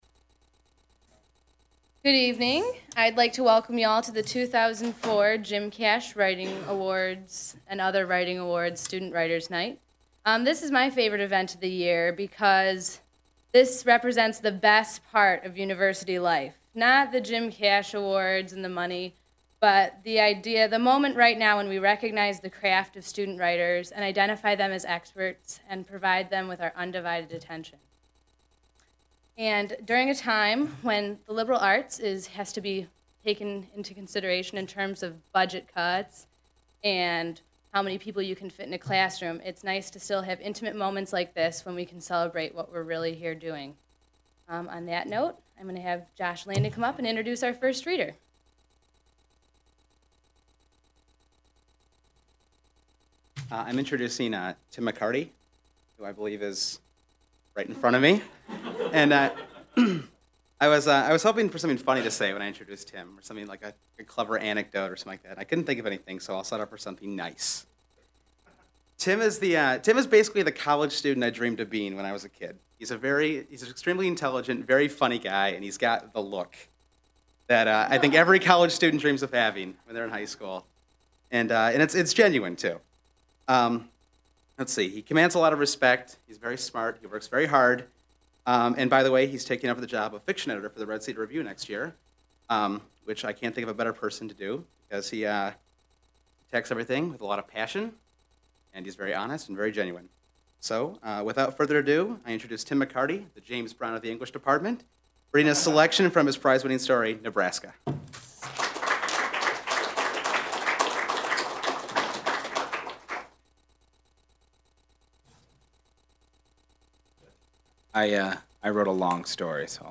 At the 2004 Michigan State University Student Writers Awards Night, students read from their original, award wining works.
Readers are introduced by staff members from the Red Cedar Review. Part of the MSU Libraries' Michigan Writers Series. Held in the MSU Main Library.